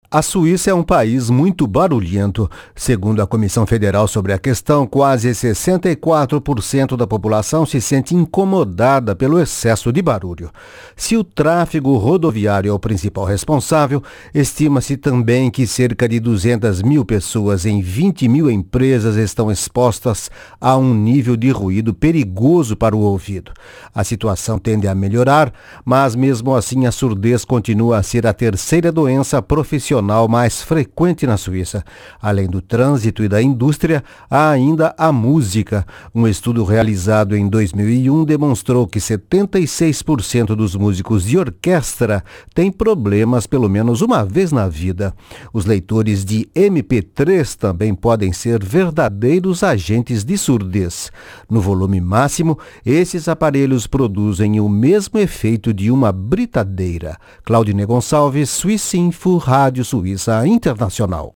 Surdo